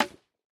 Minecraft Version Minecraft Version snapshot Latest Release | Latest Snapshot snapshot / assets / minecraft / sounds / block / bamboo / place5.ogg Compare With Compare With Latest Release | Latest Snapshot